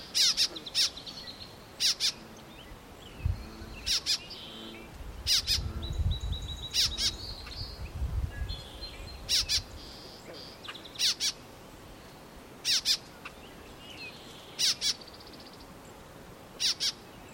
Bird Puzzler #2: Sounds Like a (Rubber) Duck?
Name the bird making the ‘squeaky toy’ call.